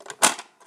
open lid loaded.aiff